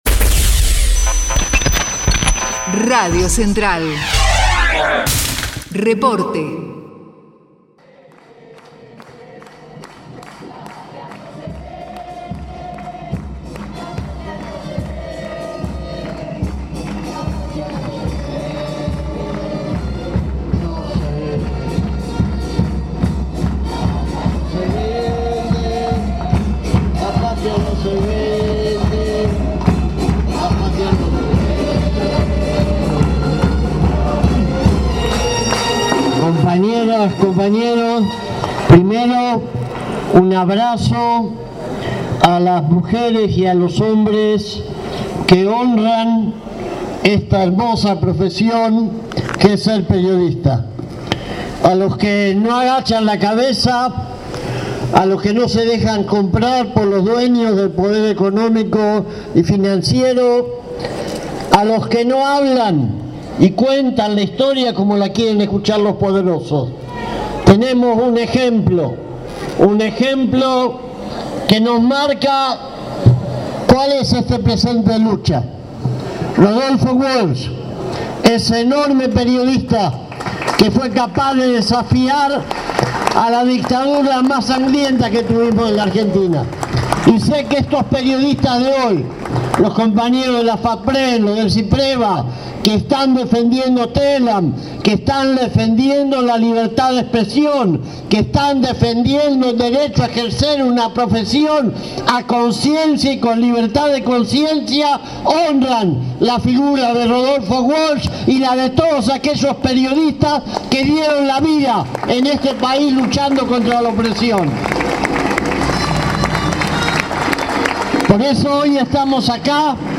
La CTA-T participó del acto por el Día de lxs Periodistas, en el acampe de lxs trabajadorxs de Télam, frente a la sede de Bolívar 531, la , con la presencia de referentes del periodismo, la comunicación y la cultura.